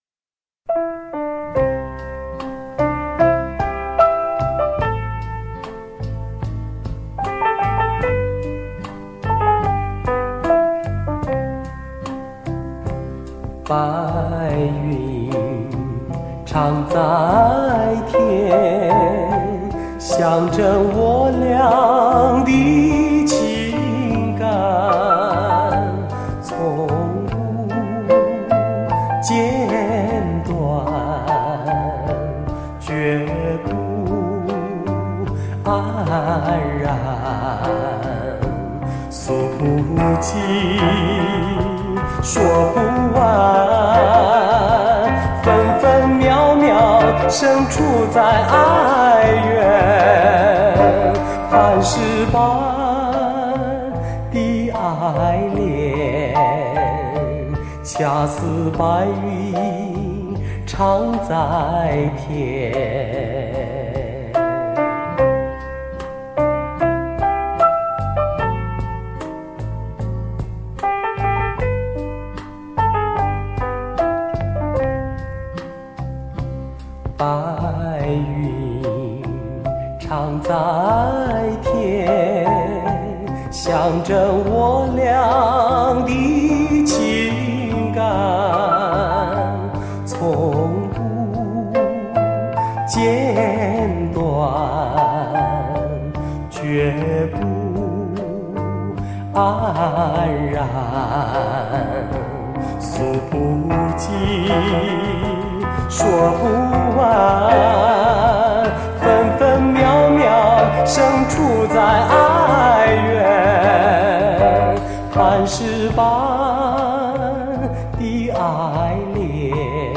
歌声嘹亮悠扬且独特